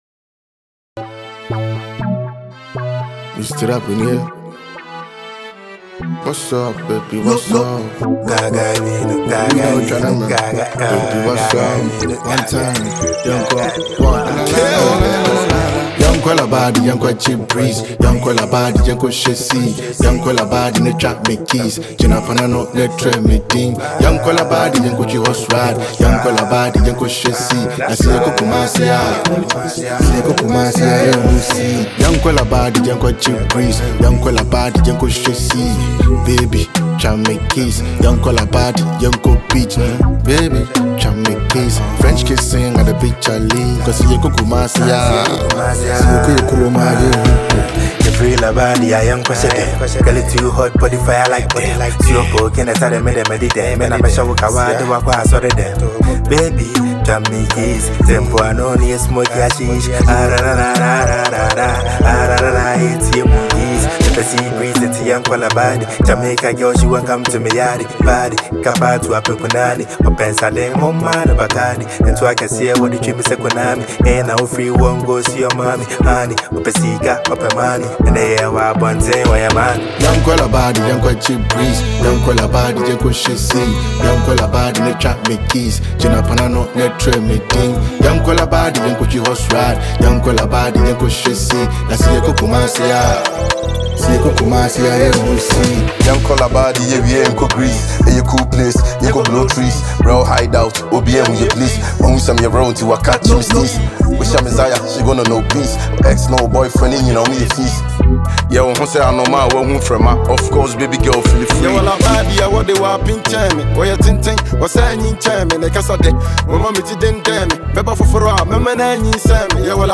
• Genre: Afrobeat / Dancehall